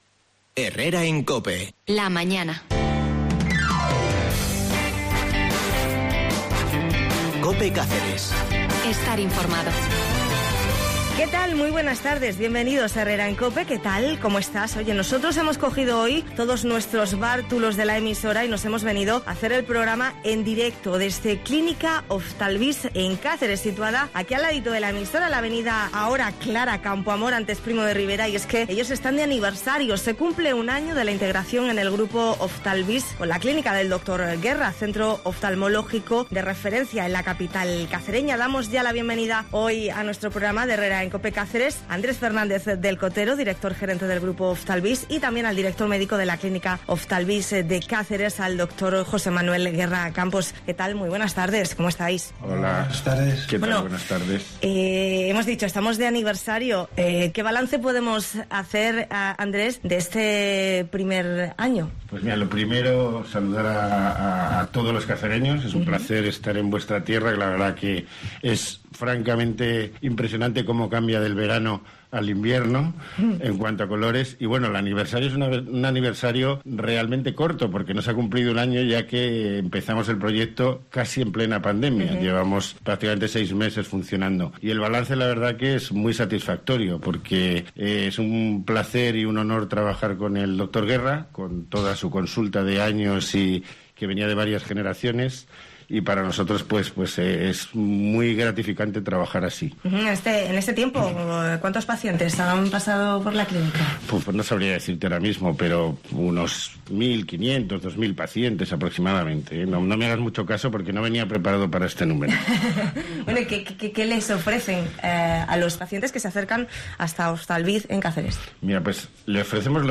AUDIO: Vuelve a escuchar Herrera en Cope Cáceres, 8 de marzo, desde la clínica Oftalvist de la capital cacereña.